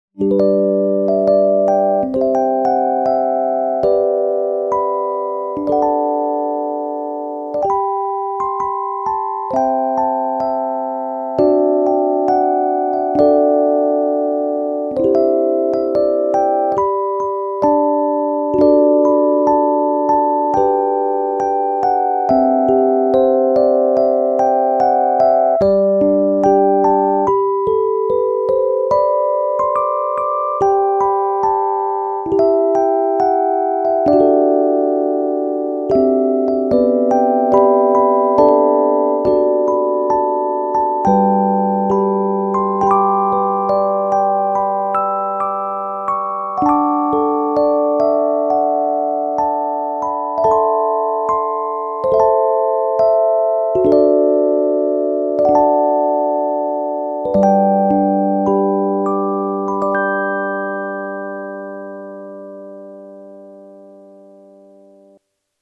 エレクトーン演奏